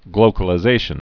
(glōkə-lĭ-zāshən)